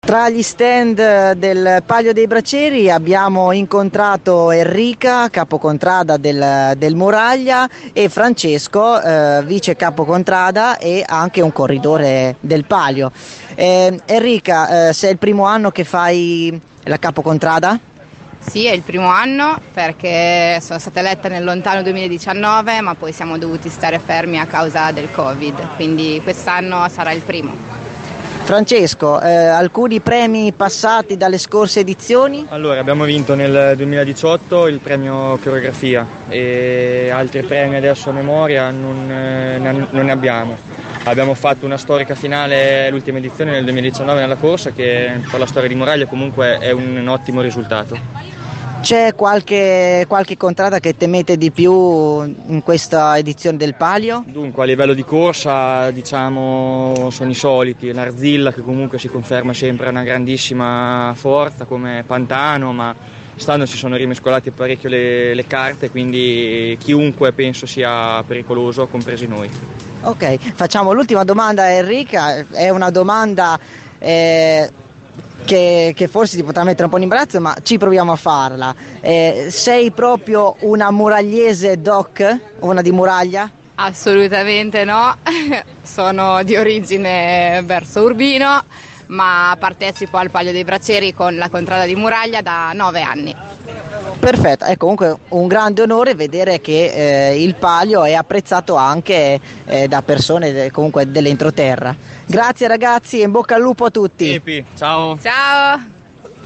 interviste-palio.mp3